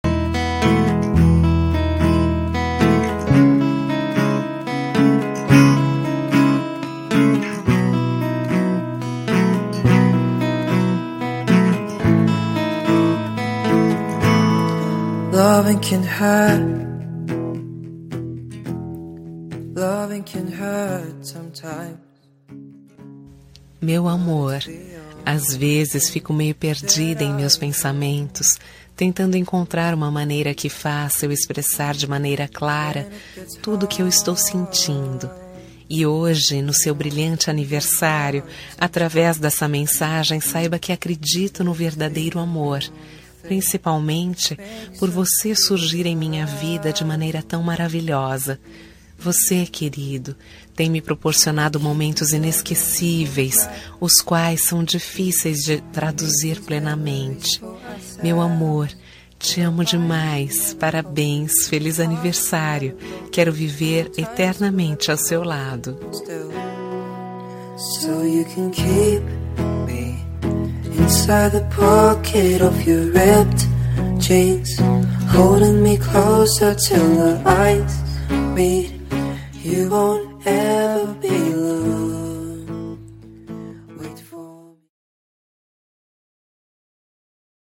Aniversário Romântico – Voz Feminina – Cód: 350322